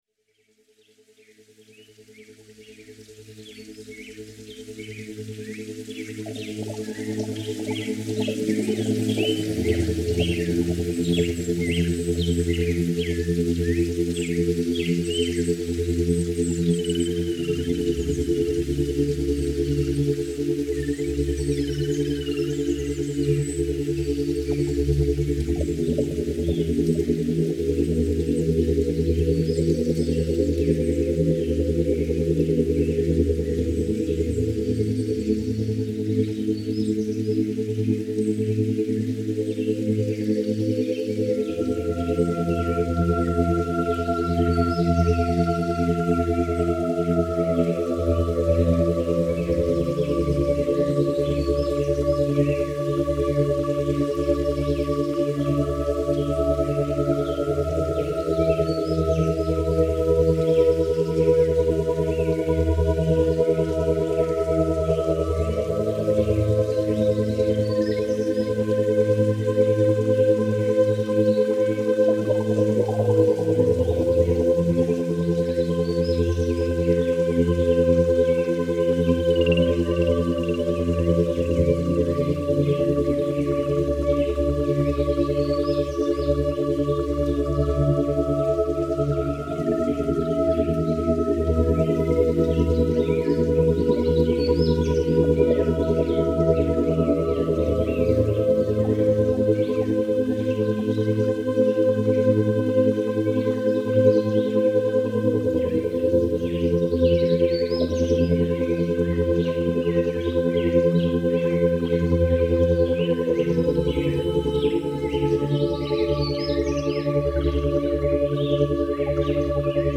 35 min CU subliminale